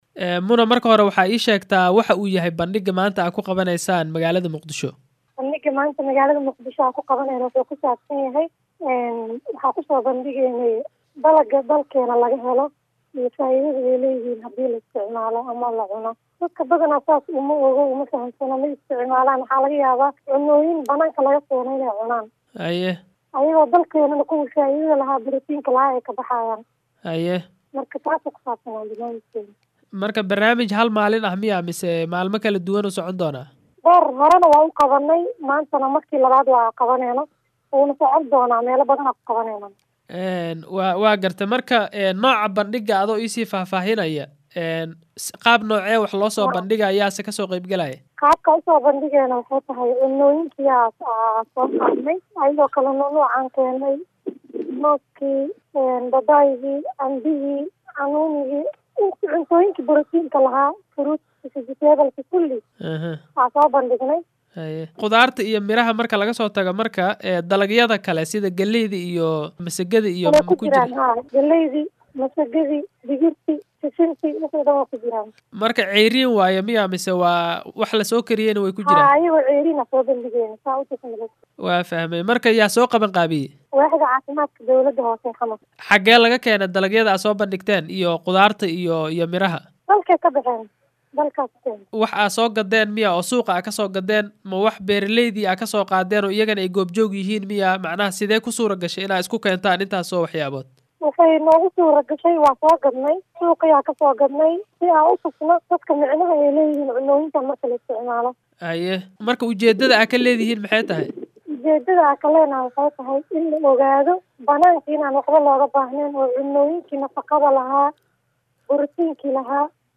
oo khadka telefoonka kula xiriiray iyadoo joogta Muqdisho ayaa la yeeshay wareysigan.